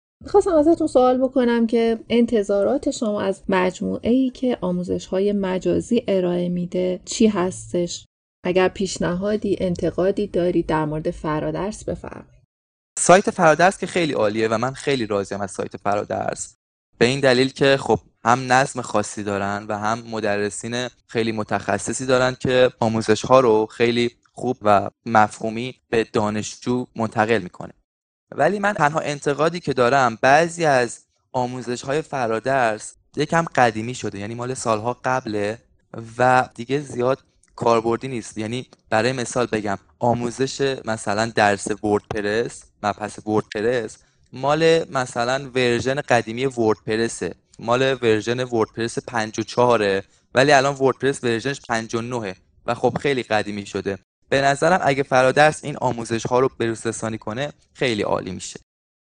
نسخه صوتی مصاحبه (فایل صوتی) به صورت مباحث جداگانه